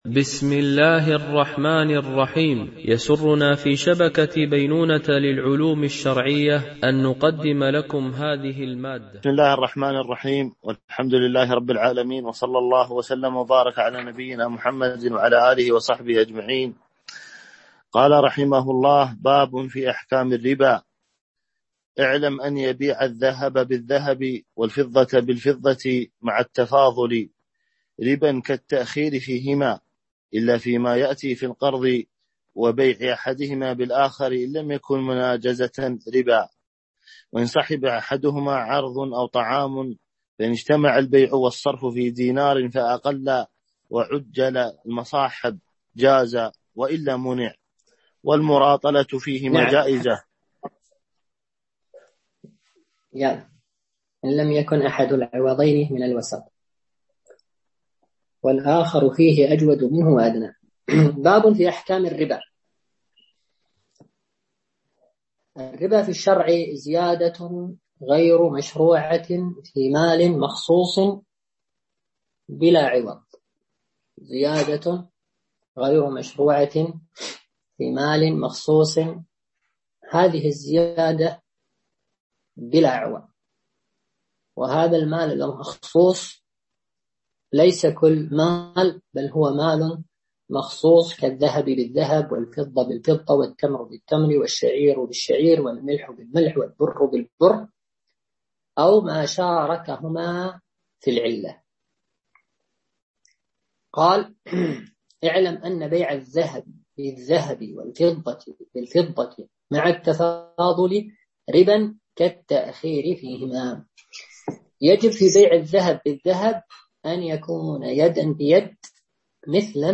الدرس 42 ( كتاب البيوع - باب في أحكام الربا